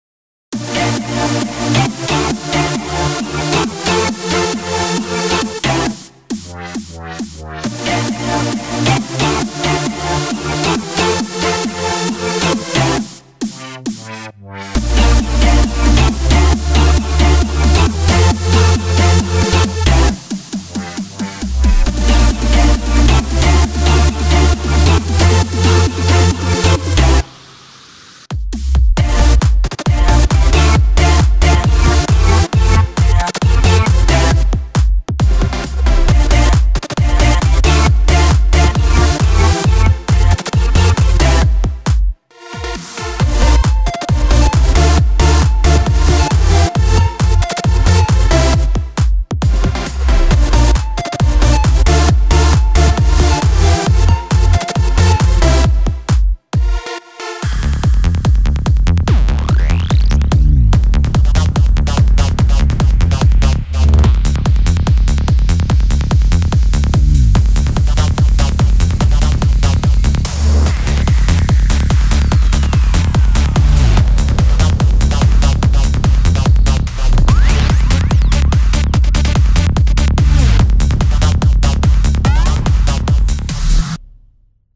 זה מקצב שכולו סאונדים מהאורגן עצמו